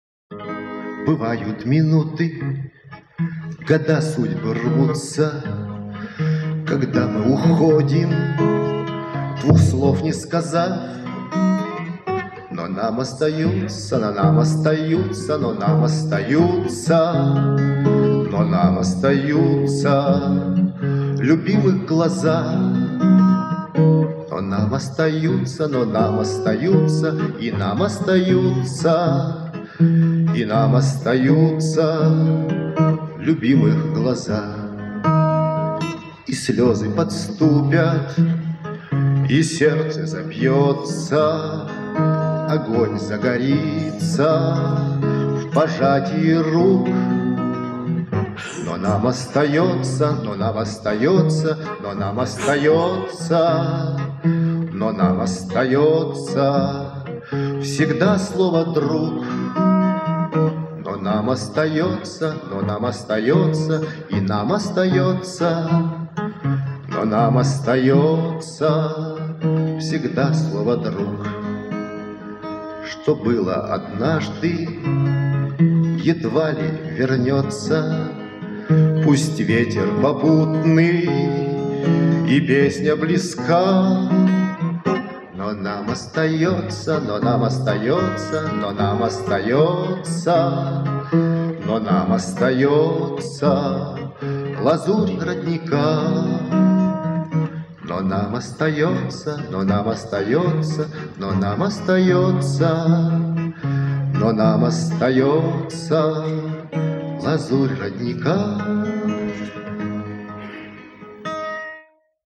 Песня в исполнении автора